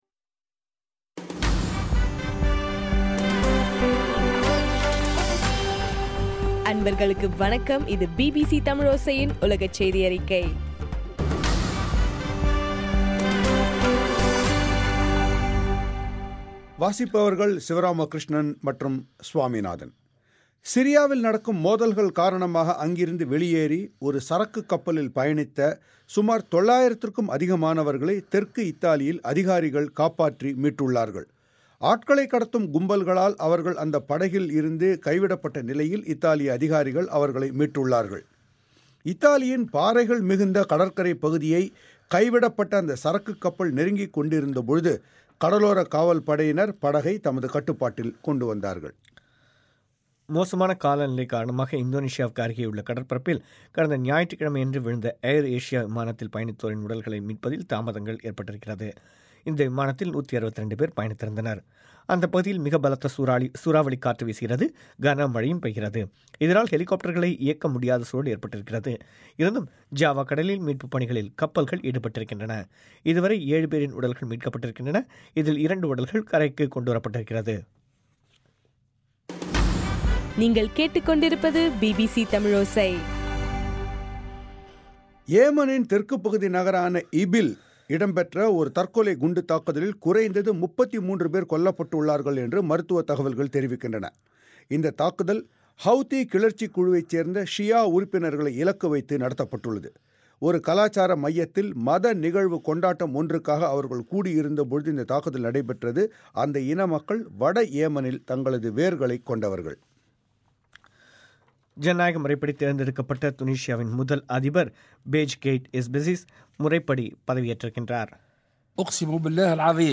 டிசம்பர் 31 2014 பிபிசி தமிழோசையின் உலகச் செய்திகள்